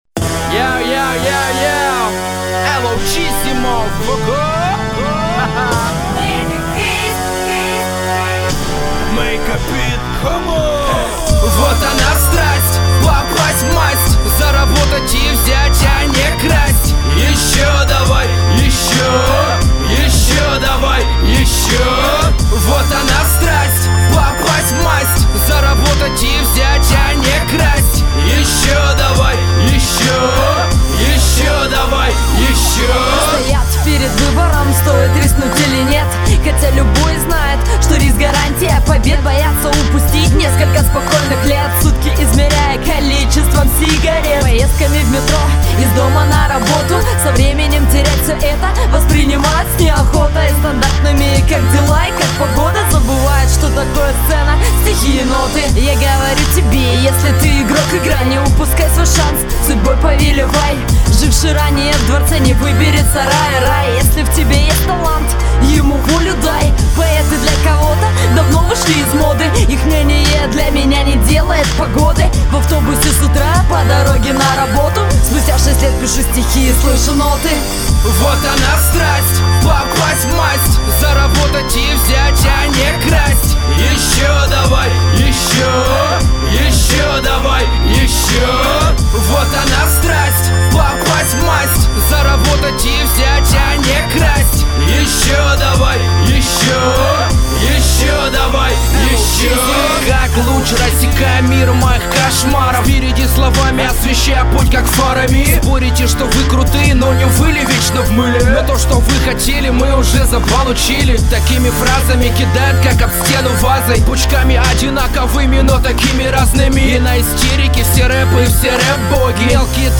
Категория: РэпЧина